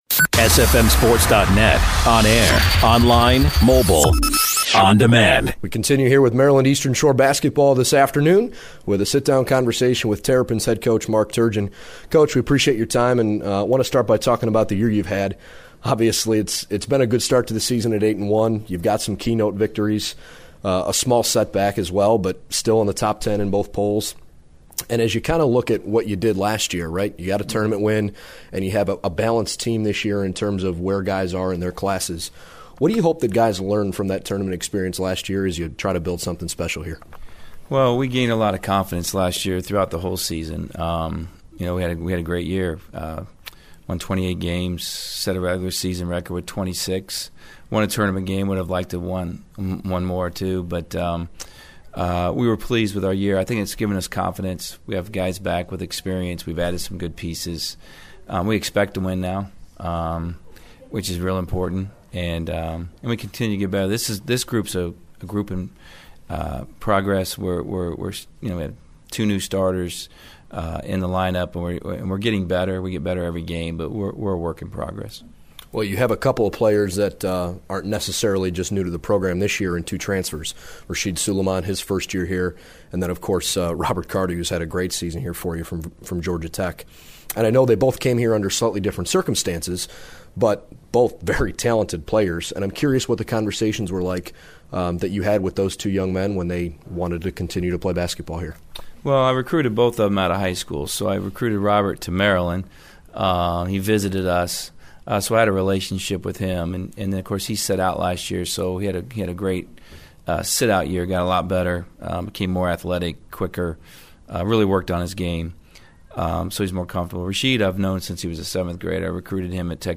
Chat with Mark Turgeon